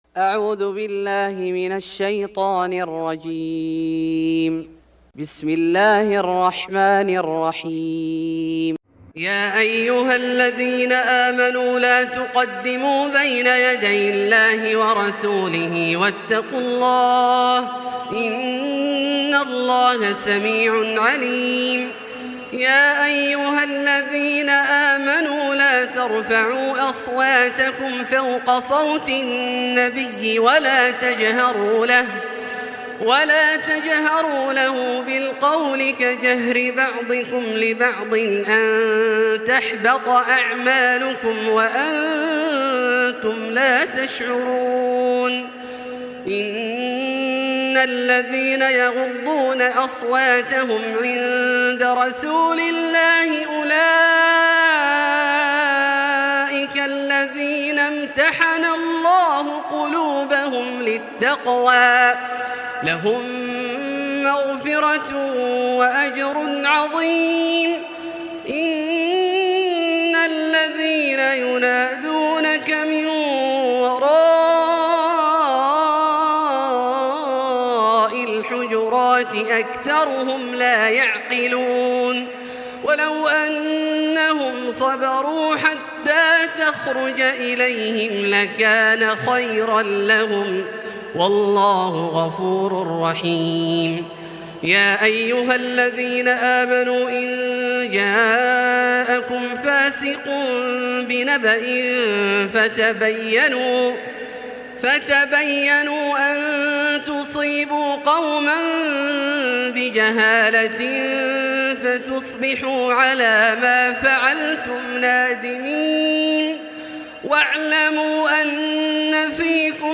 سورة الحجرات Surat Al-Hujurat > مصحف الشيخ عبدالله الجهني من الحرم المكي > المصحف - تلاوات الحرمين